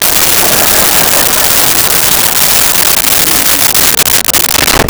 Crowd Laughing 01
Crowd Laughing 01.wav